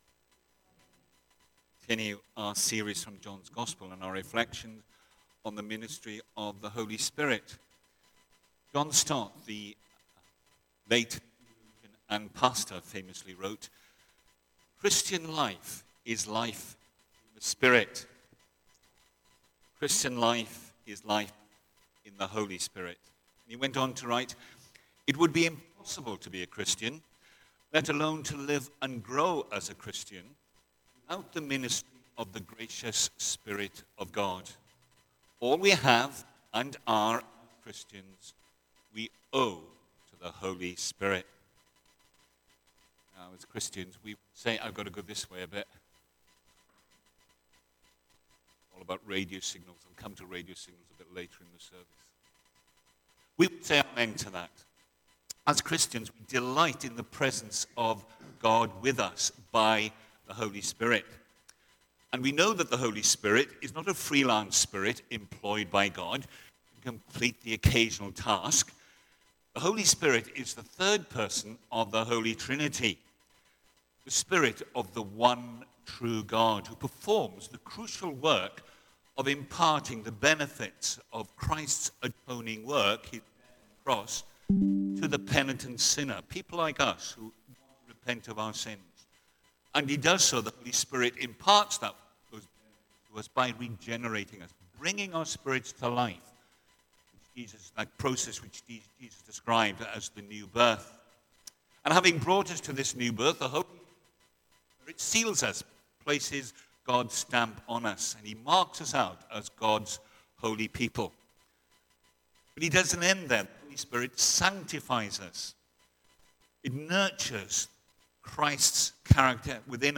Media Library The Sunday Sermons are generally recorded each week at St Mark's Community Church.
Theme: Life in the Spirit Sermon